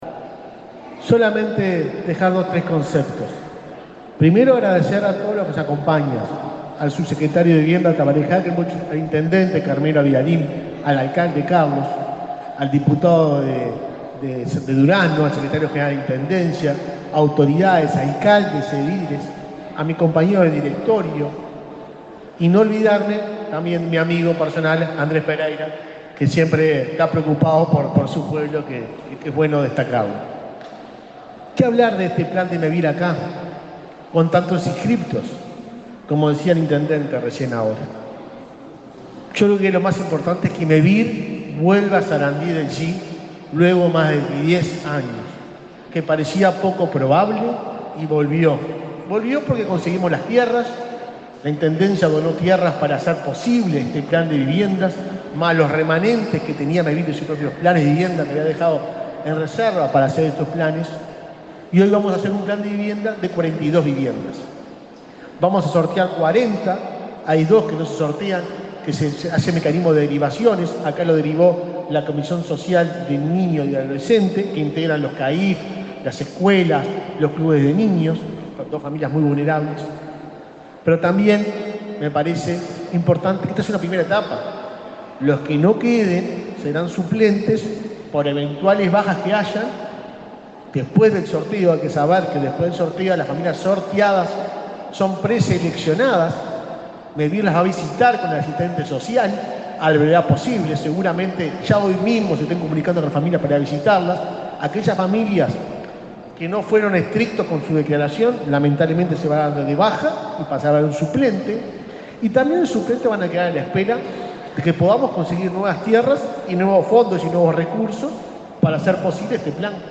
Palabras del presidente de Mevir
Este jueves 23, el presidente de Mevir, Juan Pablo Delgado, participó en el sorteo entre aspirantes a un nuevo plan de viviendas en Sarandí del Yí,